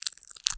High Quality Footsteps
STEPS Glass, Walk 11.wav